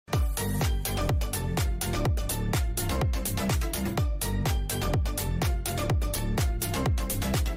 4. Музыкальная пауза (можете использовать)